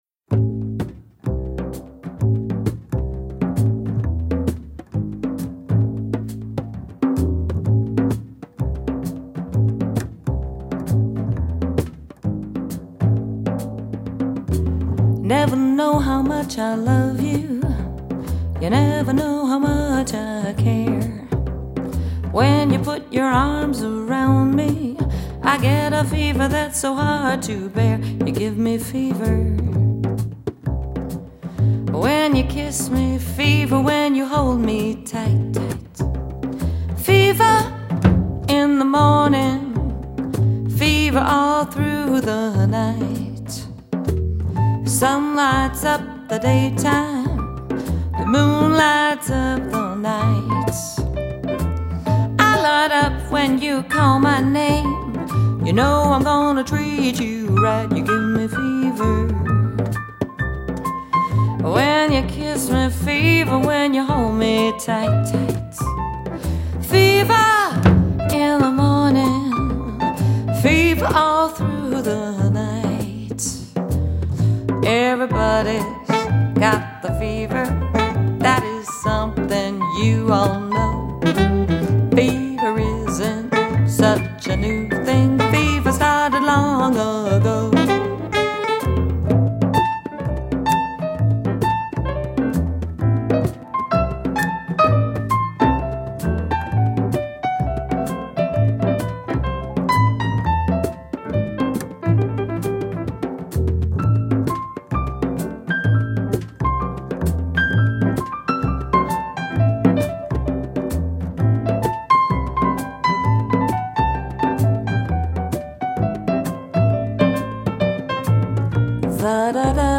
en concert
est vite devenue une figure incontournable du jazz vocal.
chant
saxophone ténor
piano
guitare
contrebasse
batterie